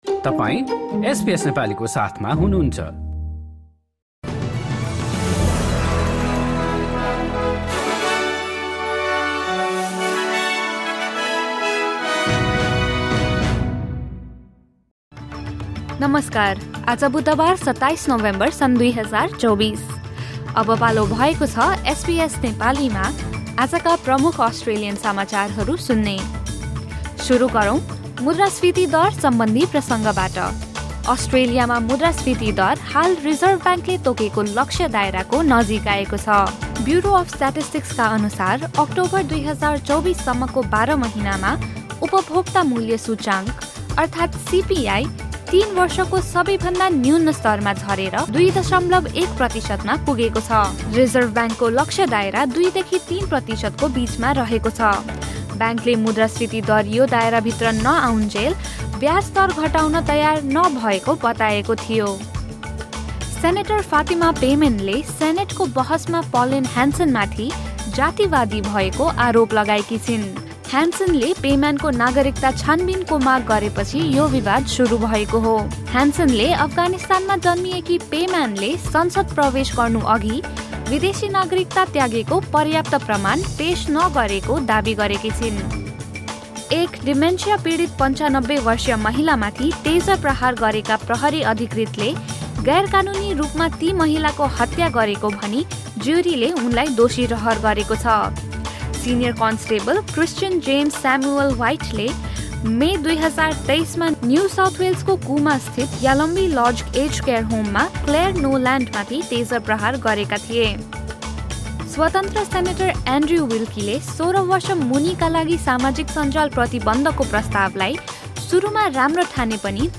SBS Nepali Australian News Headlines: Wednesday, 27 November 2024